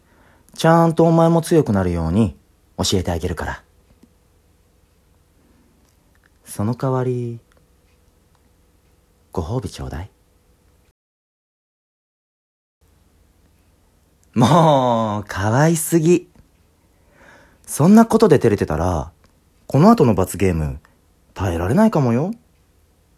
優しい彼氏とのおうちゲームデートをテーマにした、甘々シチュエーションボイスです。
包容力のある落ち着いた声で、あなたの心を癒します。